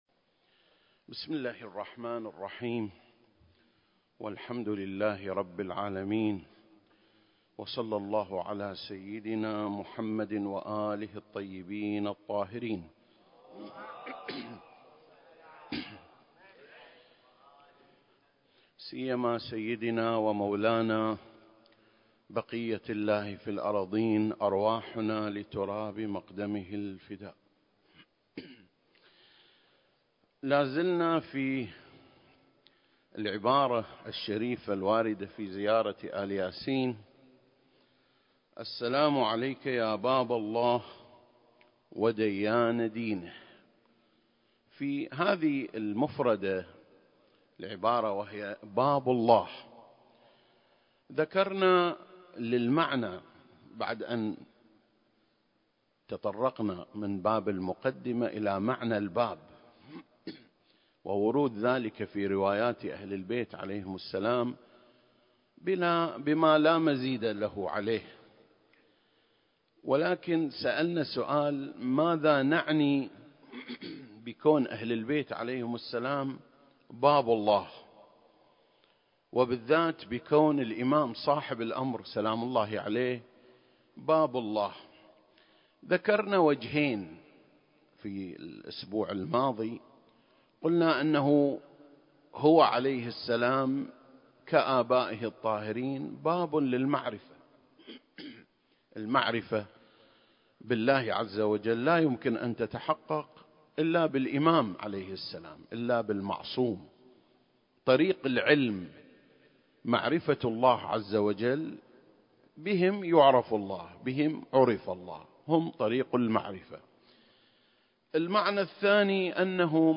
سلسلة: شرح زيارة آل ياسين (35) - باب الله (3) المكان: مسجد مقامس - الكويت التاريخ: 2021